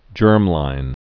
(jûrmlīn)